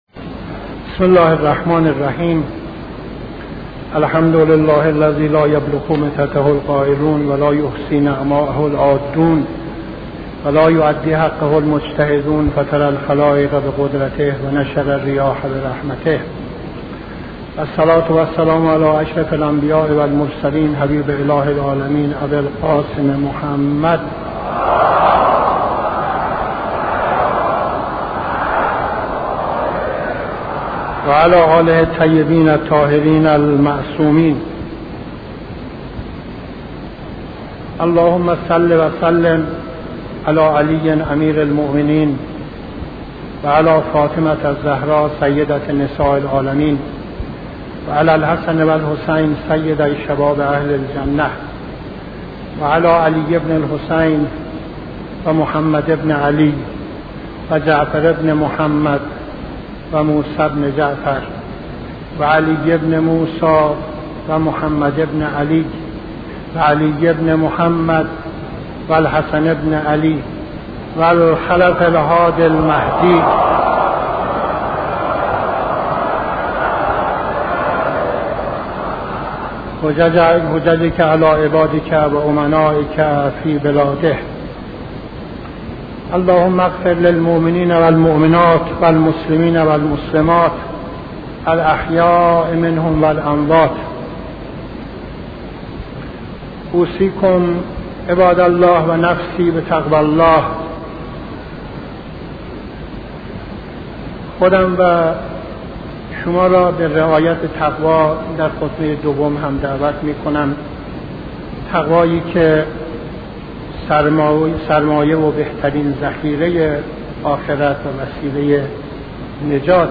خطبه دوم نماز جمعه 03-07-71